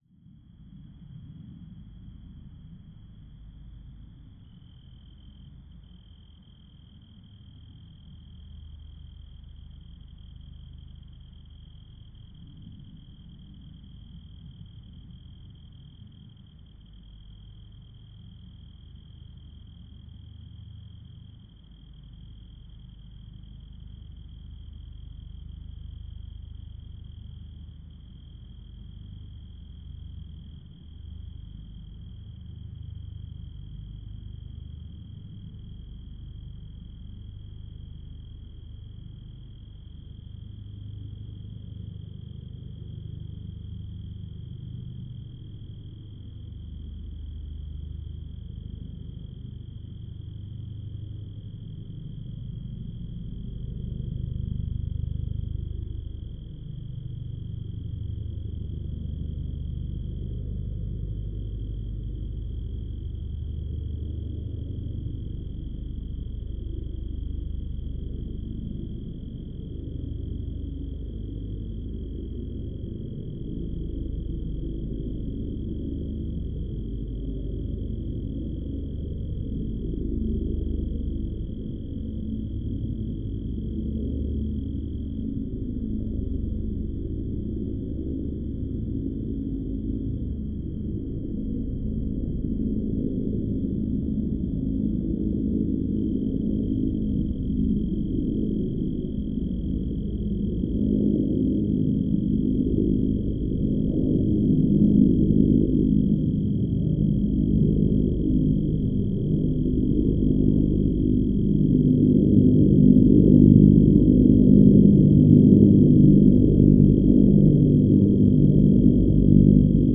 2023-10-20: Joshua Tree National Park, Passing Plane
One night the nearby military base was doing some practice fliying (I assume), and these planes made quite a few passes. For this one I filtered out sounds above 2500 Hz, which is all (or most) of the crickets.
Posted: in Field-recordings.